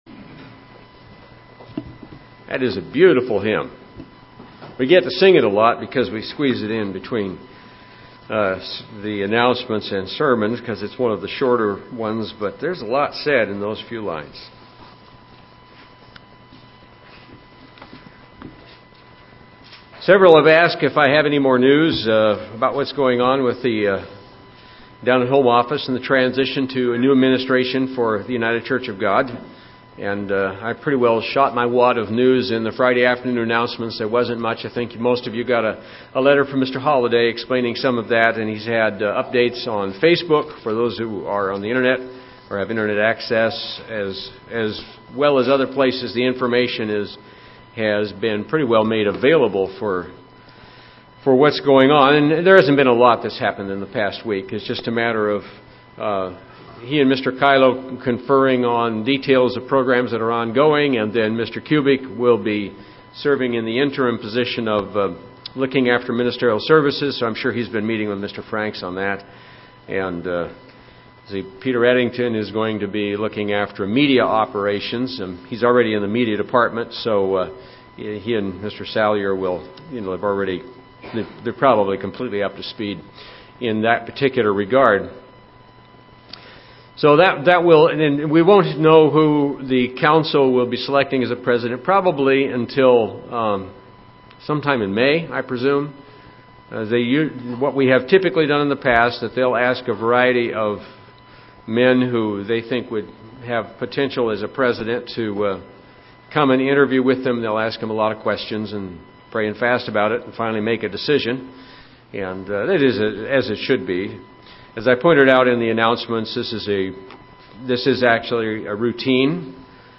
Lessons of Leadership from the Modern Era of the Church UCG Sermon Studying the bible?